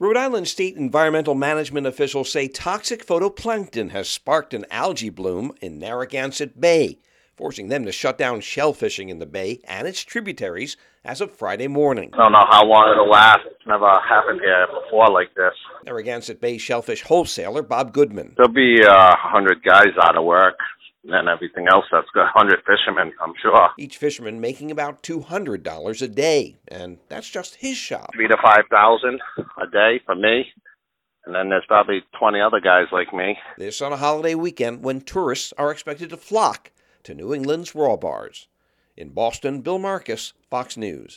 Rhode Island and Maine shut down shellfish harvesting